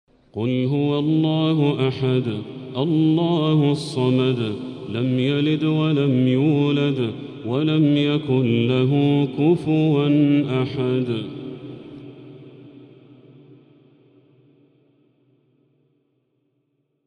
سورة الإخلاص كاملة | رمضان 1445هـ > السور المكتملة للشيخ بدر التركي من الحرم المكي 🕋 > السور المكتملة 🕋 > المزيد - تلاوات الحرمين